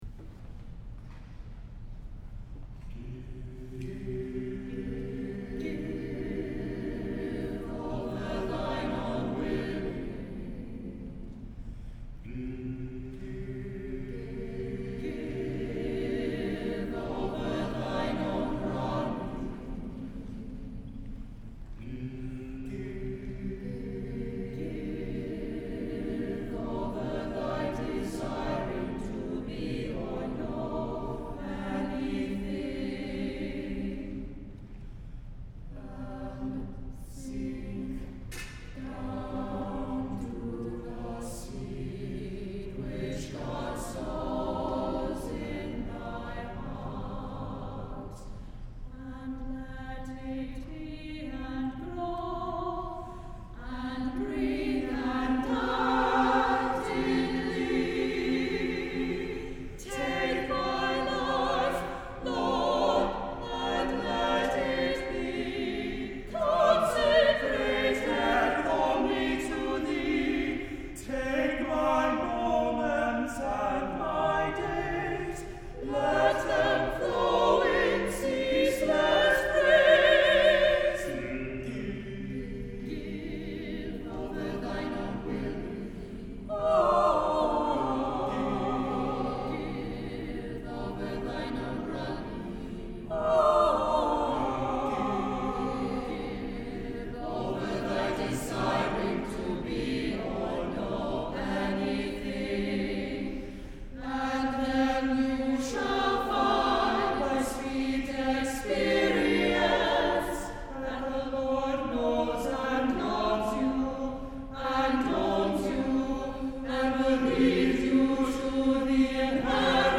for SATB Chorus (1997)